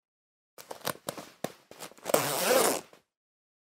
На этой странице собраны разнообразные звуки застежек и фермуаров от женских кошельков, сумок и других аксессуаров.
Звук расстегивания куртки